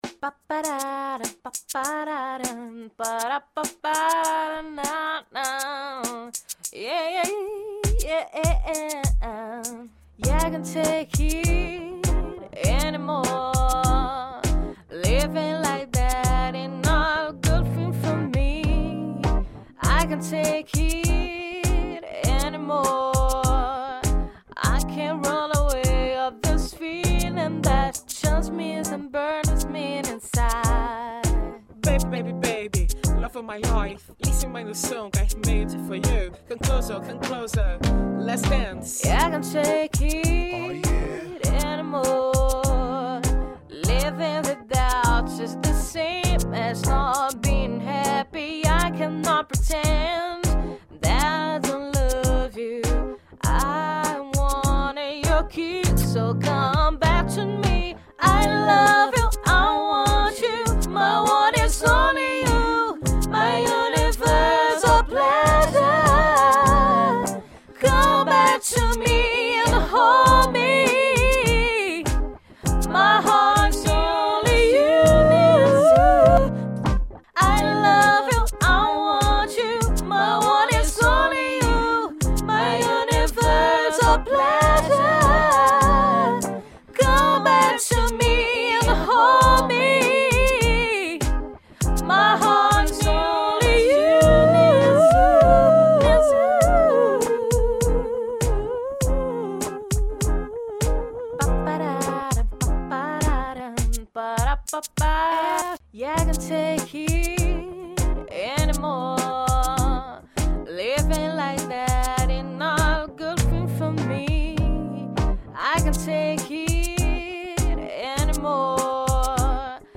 voz
guitarra
bateria